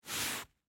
Звук провели рукой по поверхности ковра